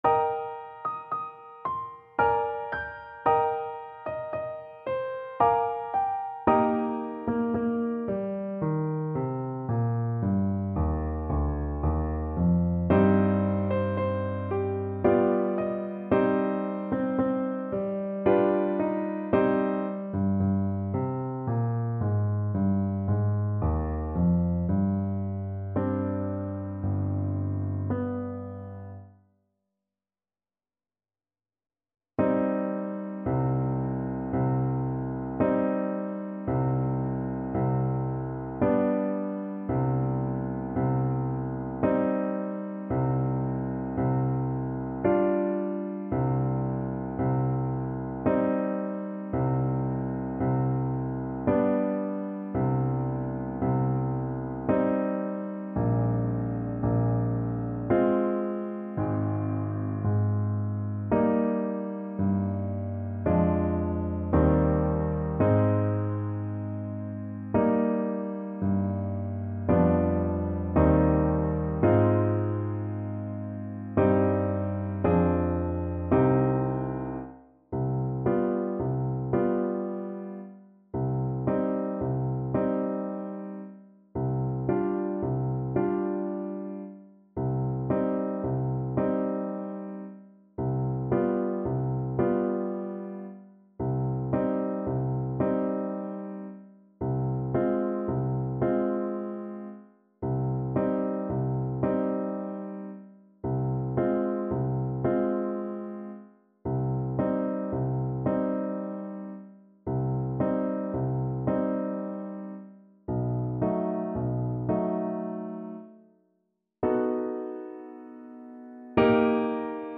Play (or use space bar on your keyboard) Pause Music Playalong - Piano Accompaniment Playalong Band Accompaniment not yet available reset tempo print settings full screen
Eb major (Sounding Pitch) Bb major (French Horn in F) (View more Eb major Music for French Horn )
3/4 (View more 3/4 Music)
~ = 56 Andante
tchaik_serenade_melancolique_HN_kar1.mp3